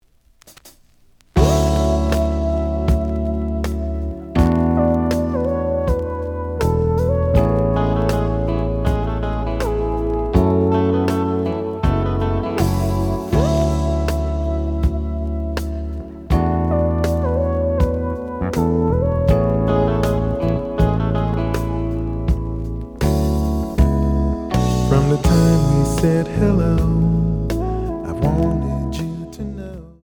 The audio sample is recorded from the actual item.
●Genre: Funk, 80's / 90's Funk
Looks good, but slight noise on both sides.)